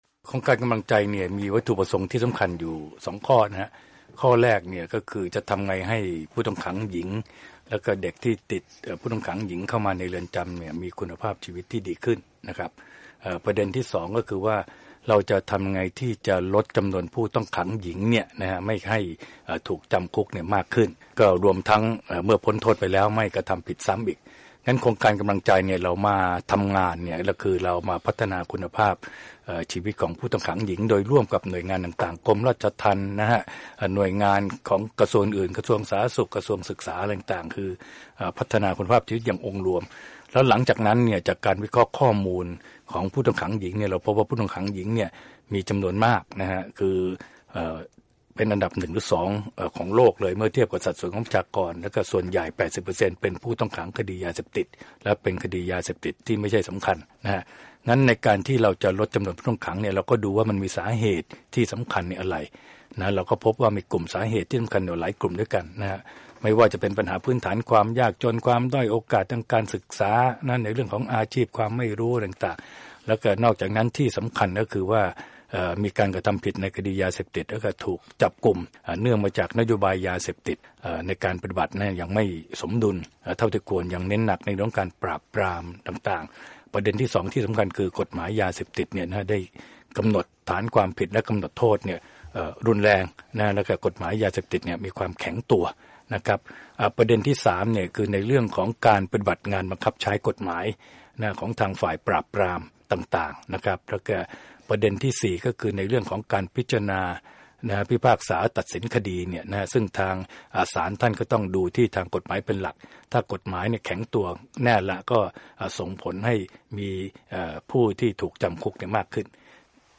Interview Inspire Project Part I